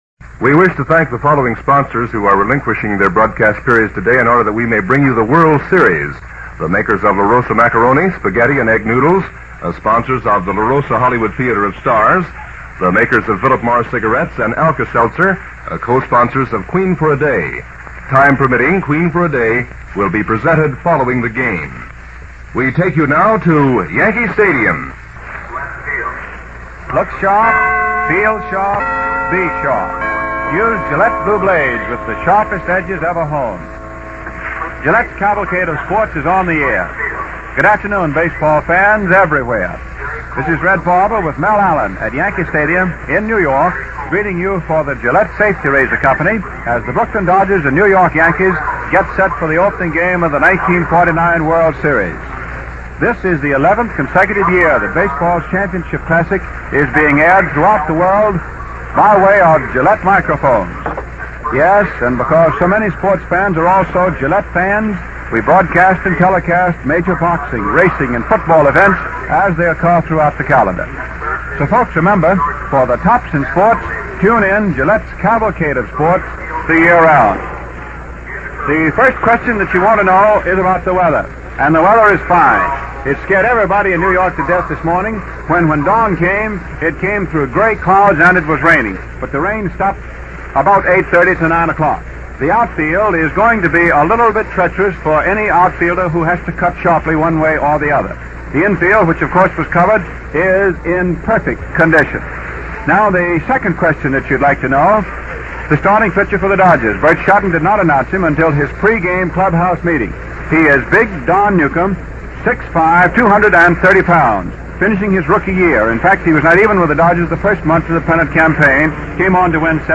World Series 1949 - What It Is, Is Baseball - Past Daily Weekend Gallimaufry - broadcast live by WOR-AM, New York - Mutual Broadcasting.